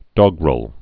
(dôgrəl, dŏg-)